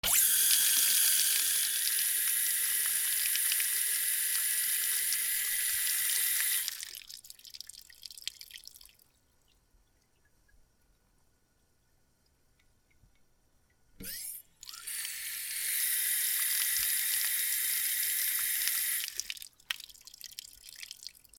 水道
/ M｜他分類 / L30 ｜水音-その他